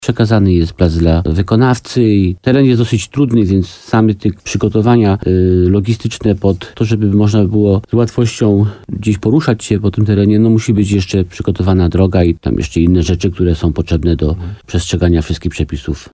Jak powiedział w programie Słowo za Słowo w radiu RDN Nowy Sącz wójt gminy Limanowa Jan Skrzekut, prace są teraz na etapie przygotowania placu pod budowę.